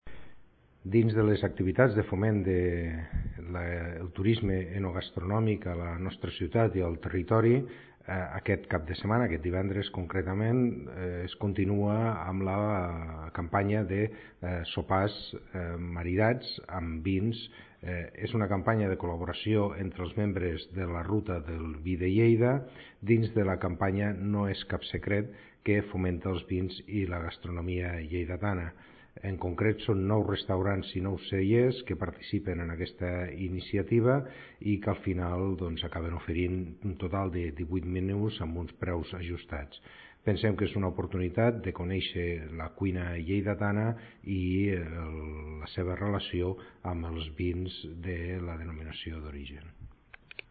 (tall de veu R. Peris) Peris ha posat de relleu que 9 restaurants i 9 cellers participen en la iniciativa que va començar el passat 4 de març.
tall-de-veu-del-tinent-dalcalde-rafael-peris